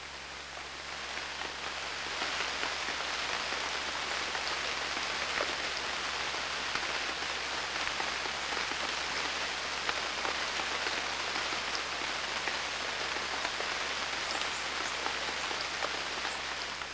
Sons da natureza 18 sons
chuva2.wav